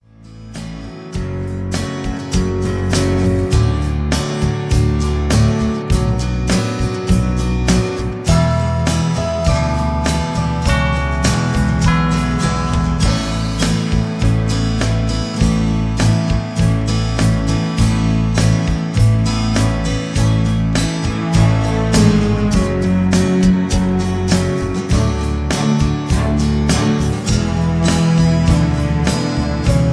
(Key-A)